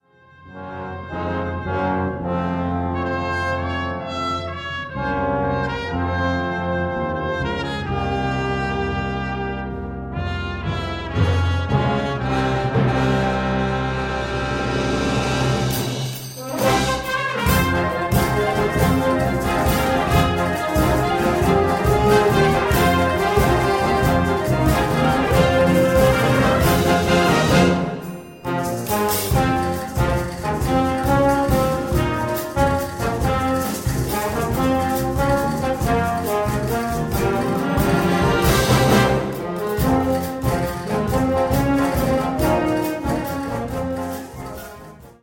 Besetzung: Blasorchester
Utilizing symphonic as well as driving, Latin-pop styles